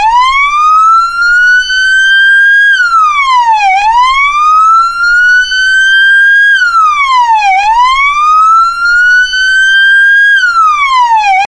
Sirens, Android, Polis Sireni